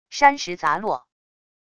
山石砸落wav音频